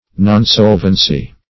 Nonsolvency \Non*sol"ven*cy\, n. Inability to pay debts; insolvency.
nonsolvency.mp3